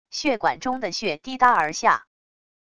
血管中的血滴答而下wav音频